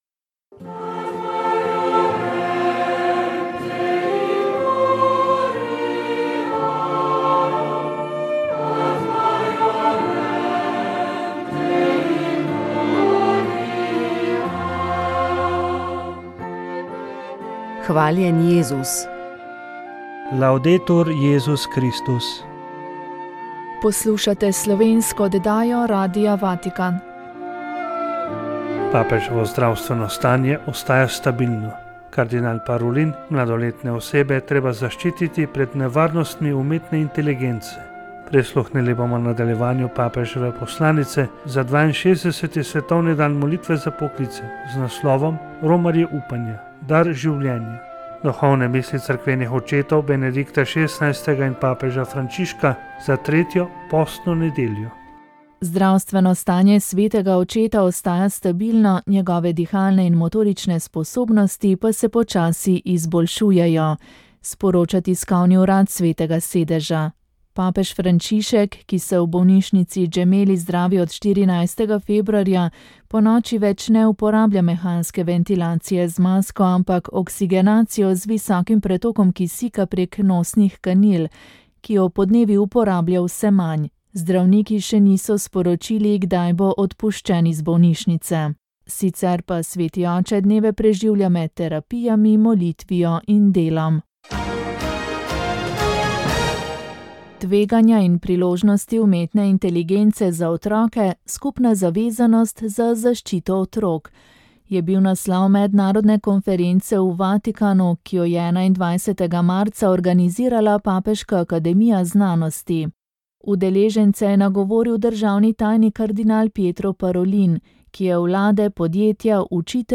V gosteh je bila temperamentna in simpatična glasbenica, ki obeležuje četrt stoletja izjemne kariere - ALYA. V klepetu je spregovorila o svojem glasbenem in osebnem zorenju, o tem, zakaj je vedno nasmejana, in še marsičem.
pogovor